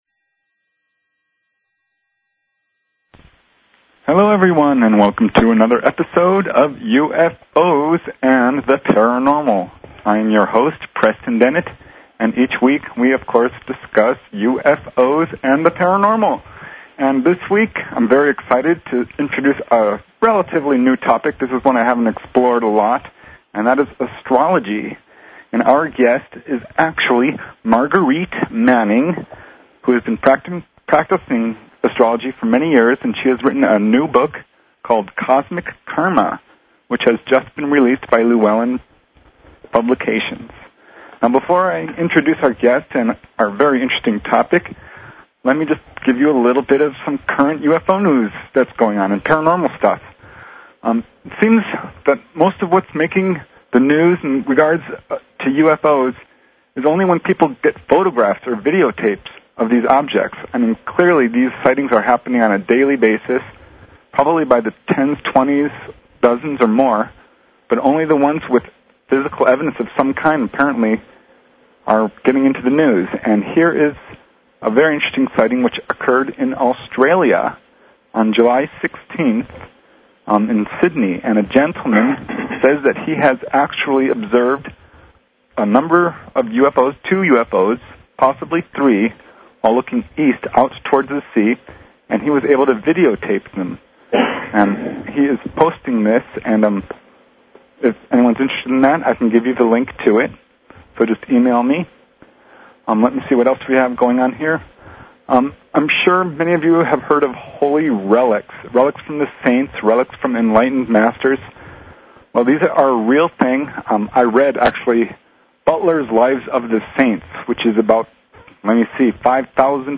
Talk Show Episode, Audio Podcast, UFOs_and_the_Paranormal and Courtesy of BBS Radio on , show guests , about , categorized as
A very lively and popular show with lots of callers.